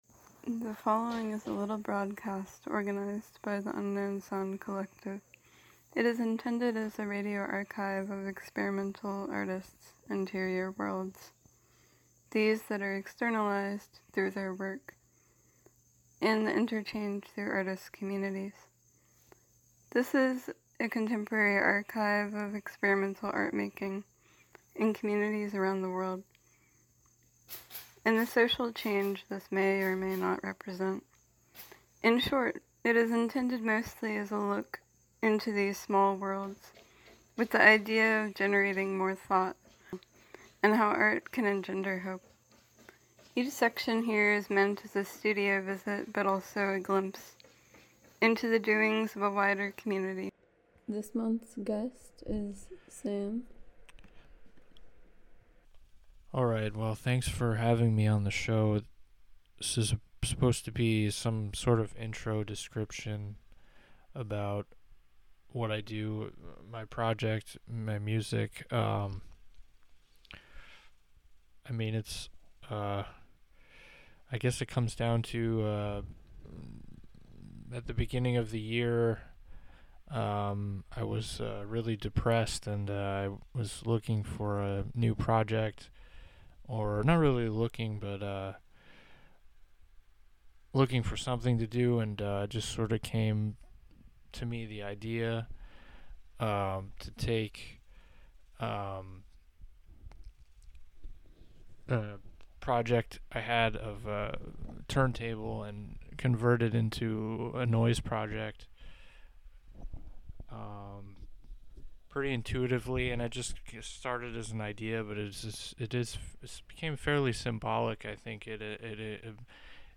"unknown sounds" is intended as a radio archive of experimental artists’ interior worlds, these that are externalized through their work, and the interchange through artist communities. This is an contemporary archive of experimental art-making in communities around the world, the inherent politicism of art, and the social change this may or may not represent.
Each section here is meant as a studio visit, but also a glimpse into the doings of a wider community and the cultural, political repercussions of experimental sound and art-making.